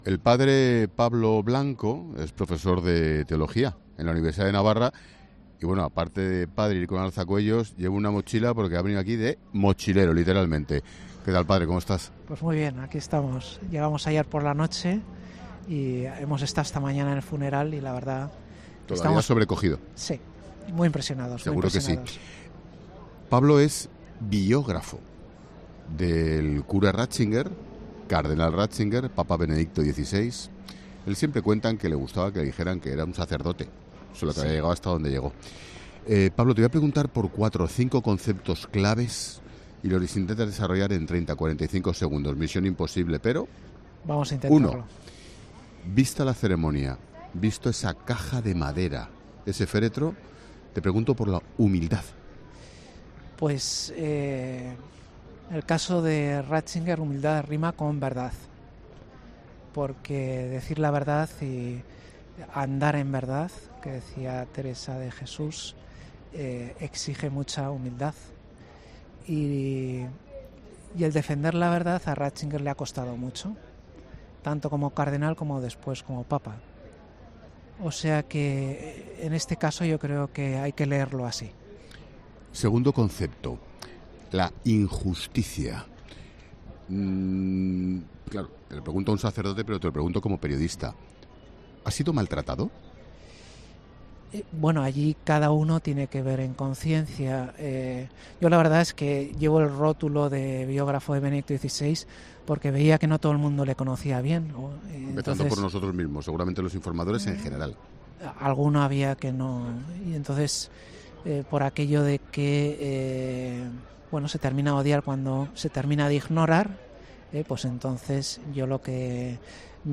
“Su carisma es diferente, es un carisma intelectual, de análisis y diagnóstico, un carisma de diseñar una reforma que el Papa Francisco está cumpliendo, tanto en el caso de abusos como de transparencia financiera, que está ideado por una mente brillante como es la de Ratzinger”, subraya en los micrófonos de COPE desde Roma.